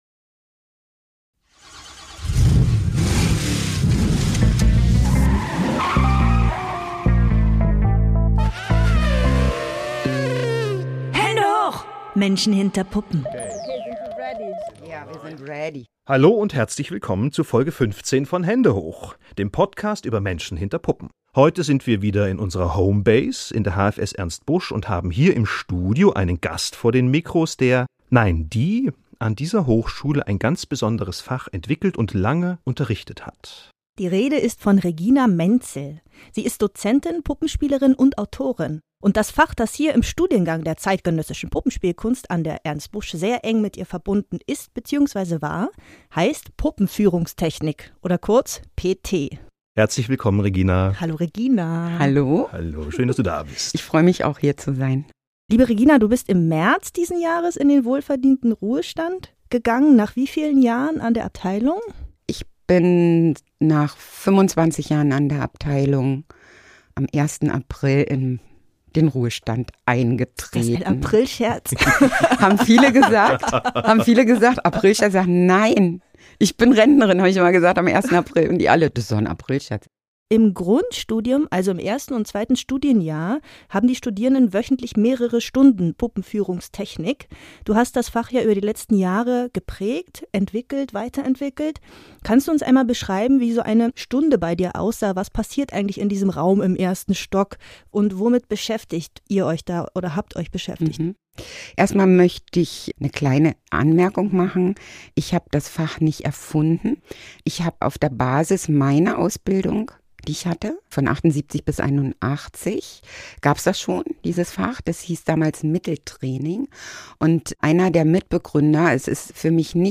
HÄNDE HOCH kommt ins Gespräch mit Künstler*innen der Szene der zeitgenössischen Puppenspielkunst über ihre individuellen Lebenswege - und Umwege.